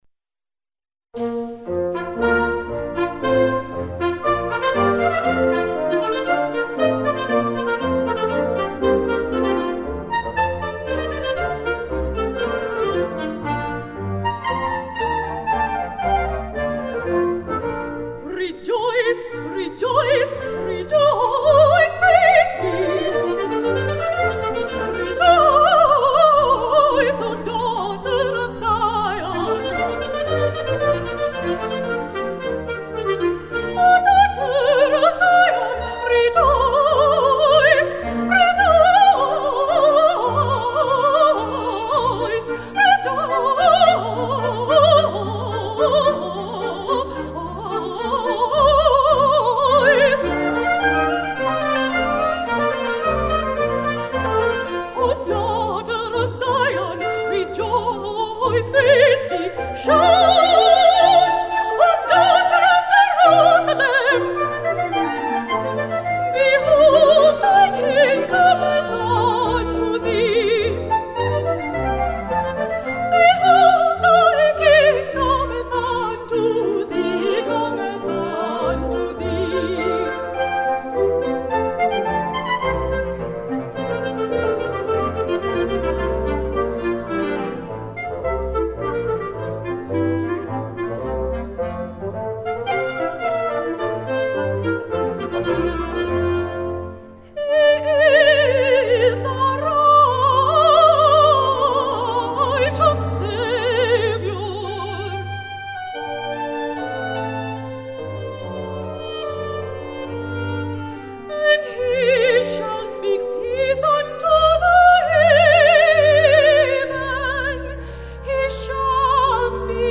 Voicing: Vocal Solo w/ Band